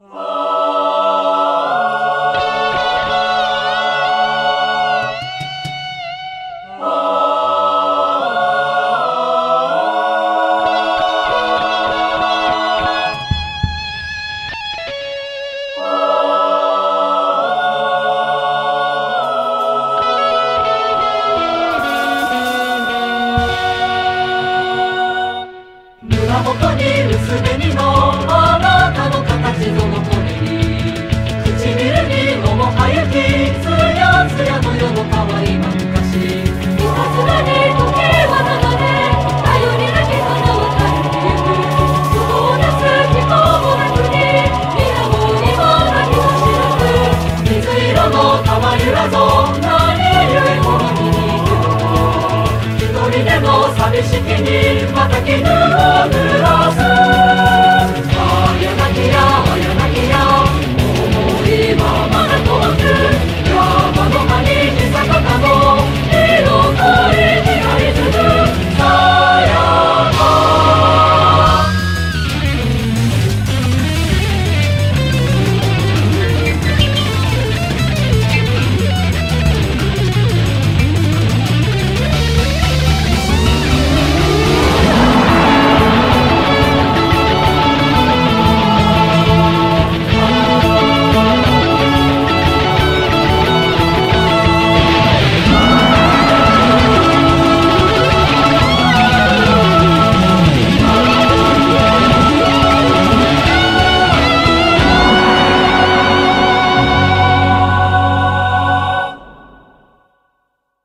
BPM180